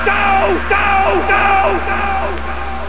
Homer saying "DOH!" with an echo